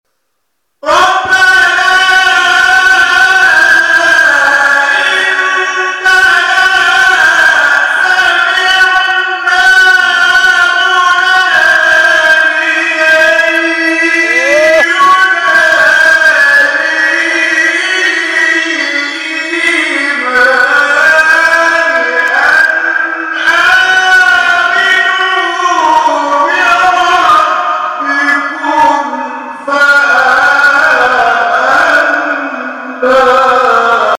به گزارش خبرگزاری بین‌المللی قرآن(ایکنا) مقاطع صوتی از تلاوت قاریان بین‌المللی و ممتاز کشور که به تازگی در شبکه‌های اجتماعی منتشر شده است، ارائه می‌شود.